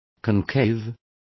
Complete with pronunciation of the translation of concave.